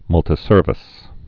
(mŭltĭ-sûrvĭs)